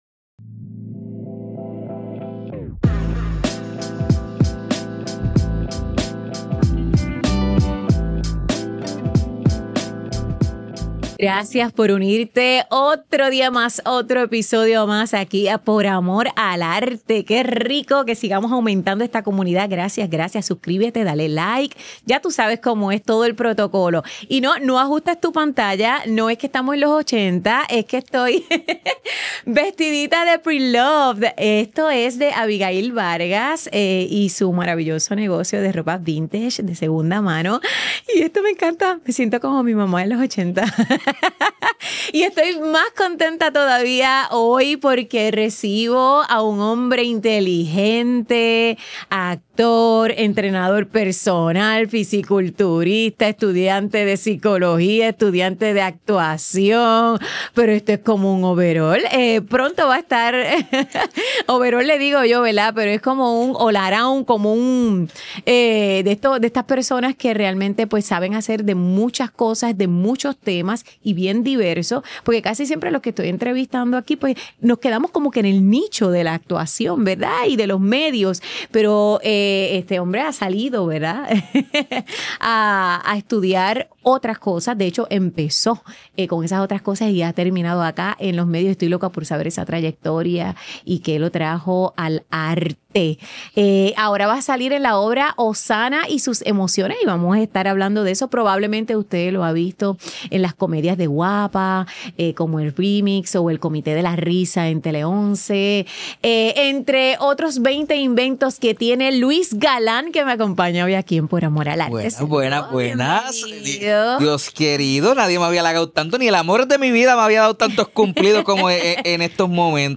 Grabado en GW-Cinco Studio para GW5 Network